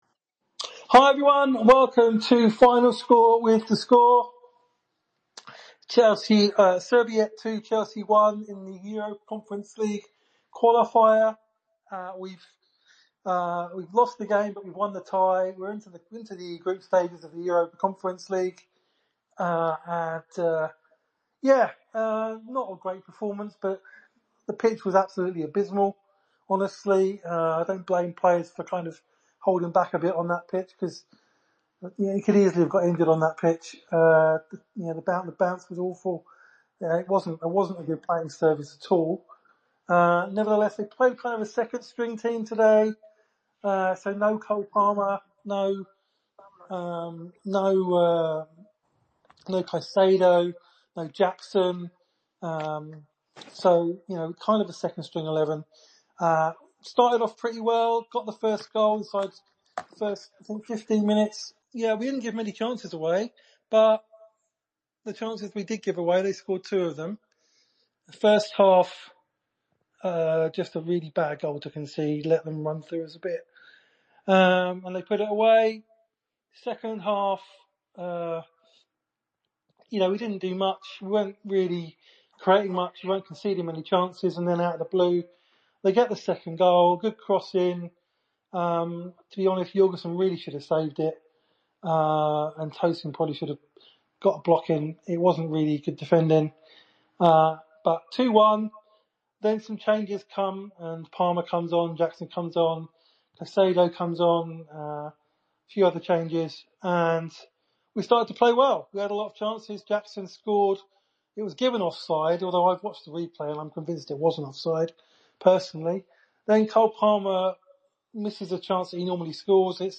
The lads chat all about it post-game...